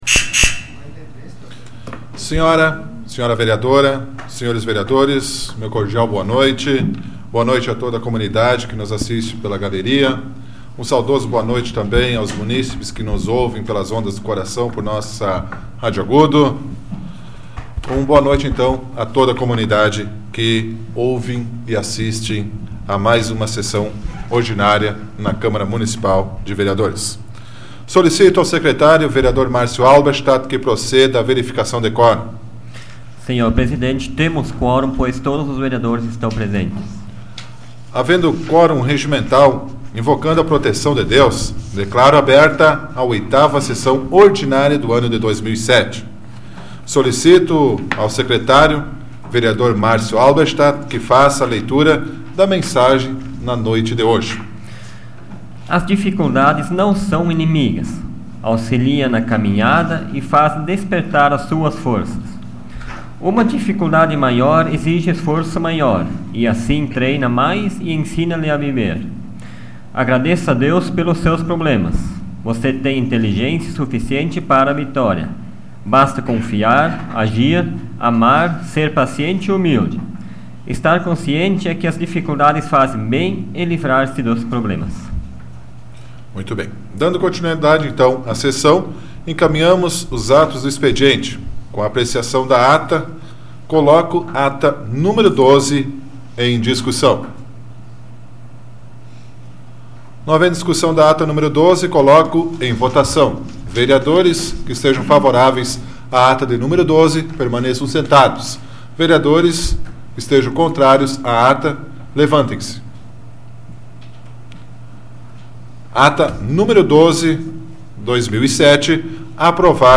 Áudio da 82ª Sessão Plenária Ordinária da 12ª Legislatura, de 23 de abril de 2007